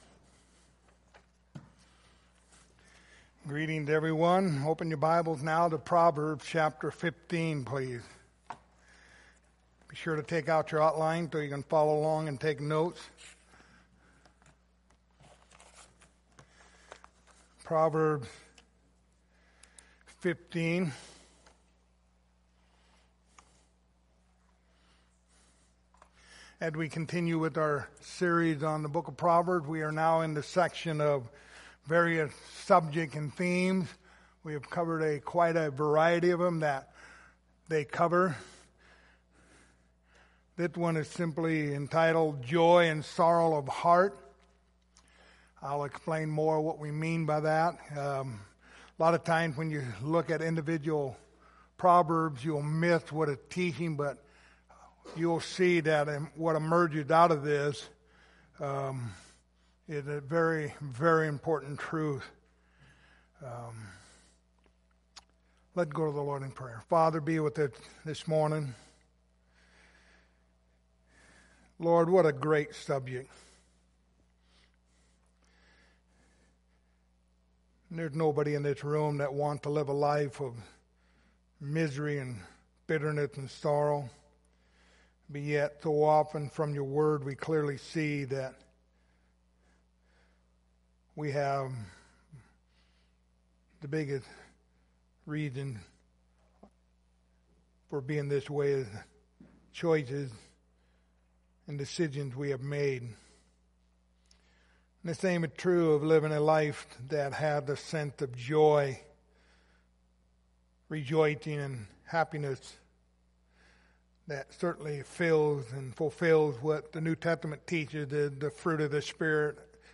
Passage: Proverbs 10:28 Service Type: Sunday Morning